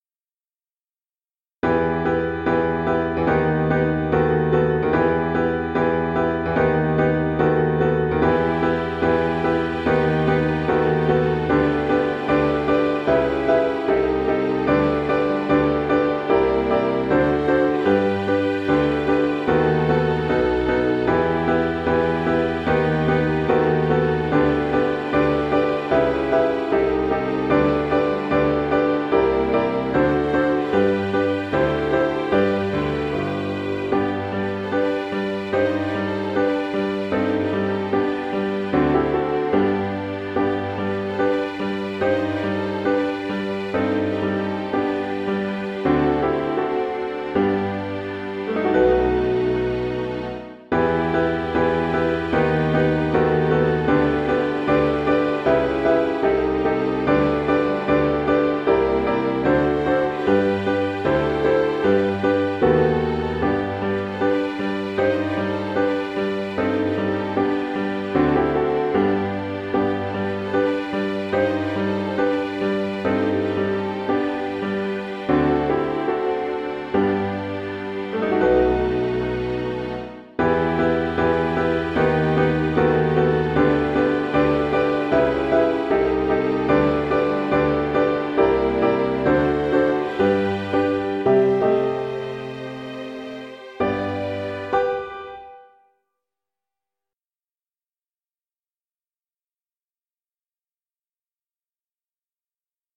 rehearsal recording